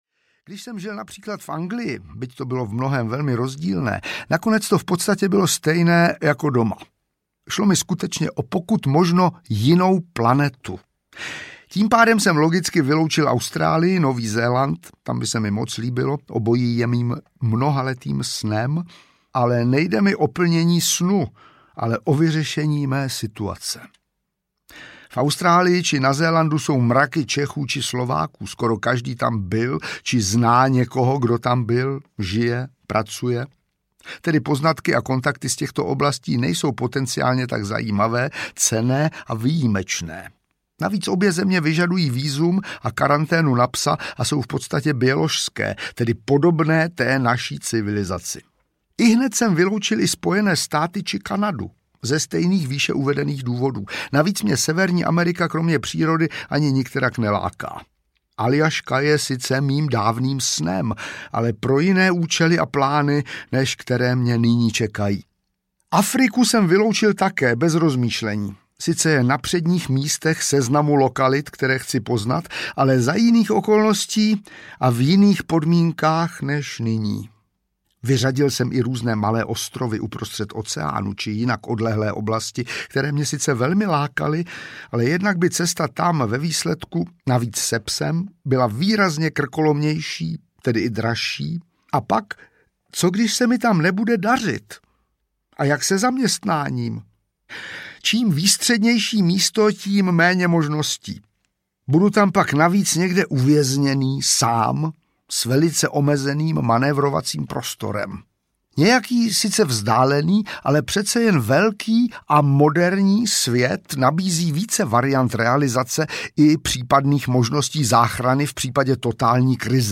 Ukázka z knihy
• InterpretVáclav Vydra ml.